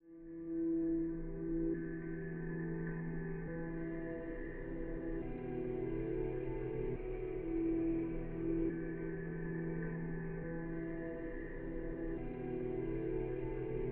Texture (5).wav